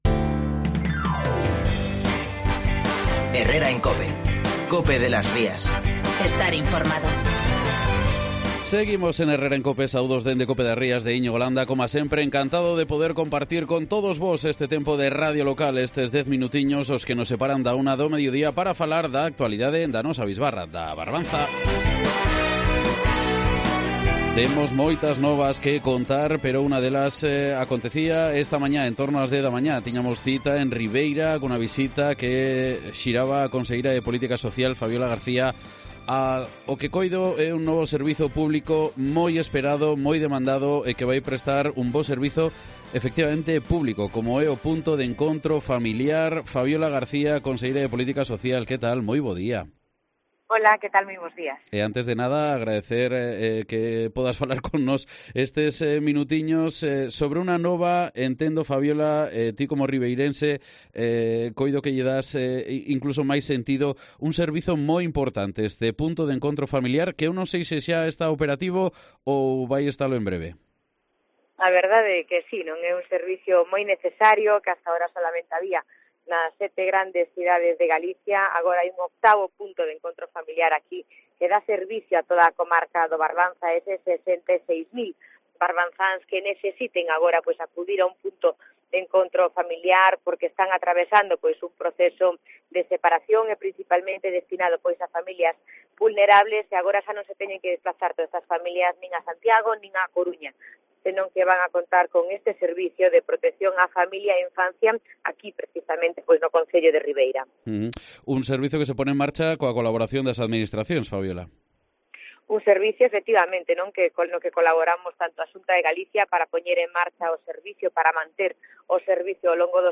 Entrevista en COPE das Rías con la titular de Política Social, Fabiola García, sobre el nuevo PEF de Ribeira